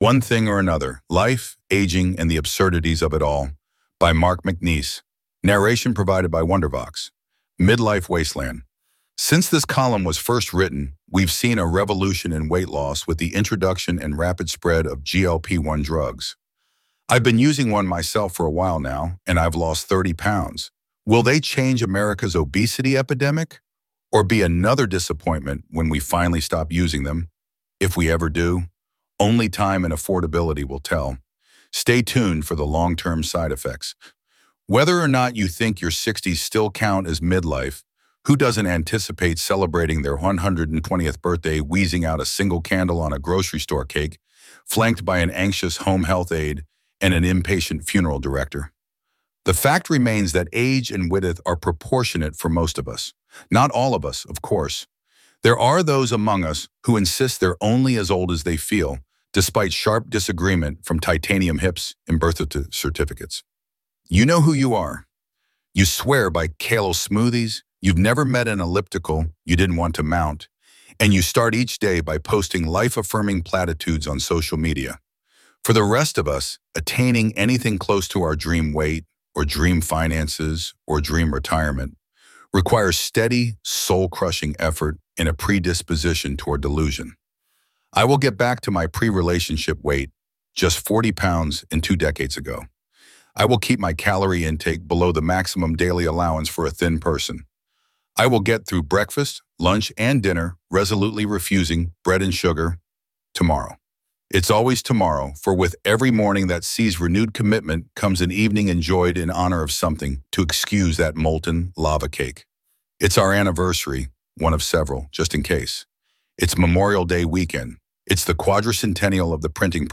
Narration provided by Wondervox